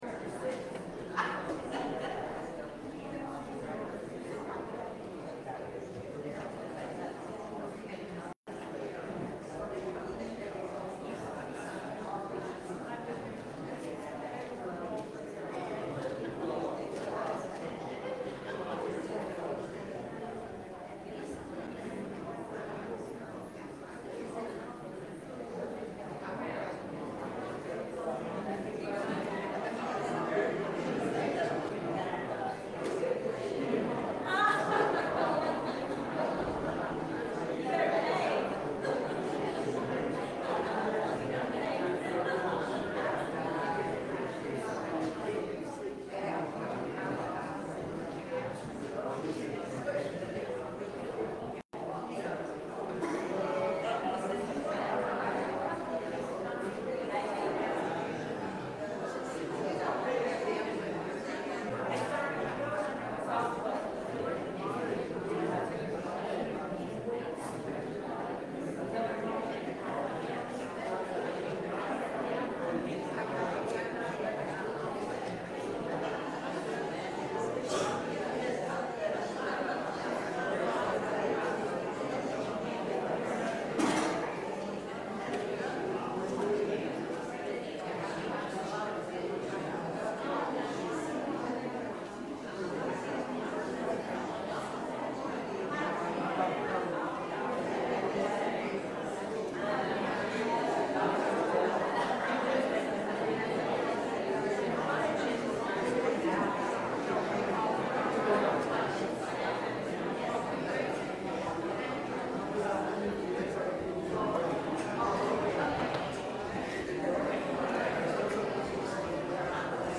The Directors – Concert